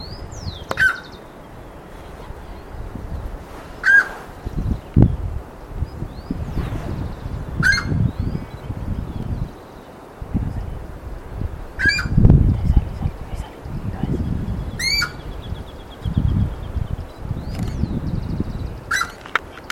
Saracuruçu (Aramides ypecaha)
Nome em Inglês: Giant Wood Rail
Fase da vida: Adulto
Localidade ou área protegida: Parque Costero del Sur
Condição: Selvagem
Certeza: Observado, Gravado Vocal